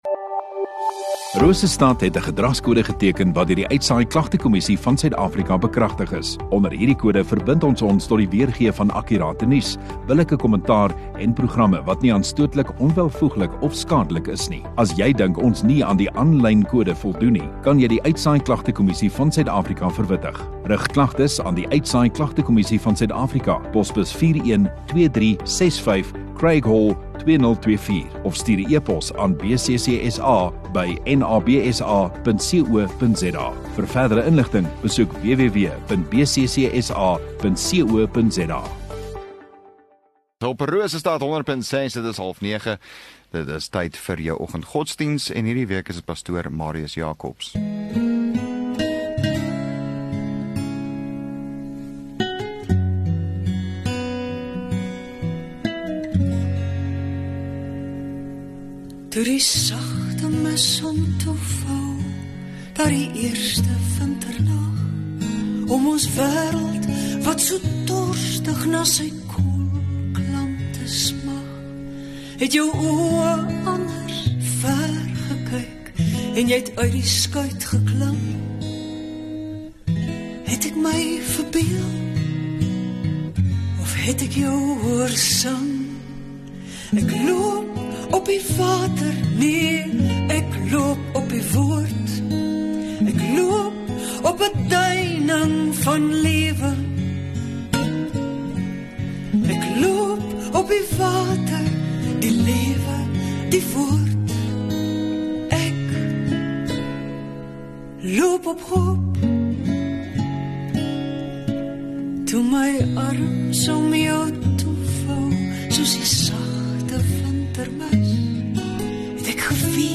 27 Feb Donderdag Oggenddiens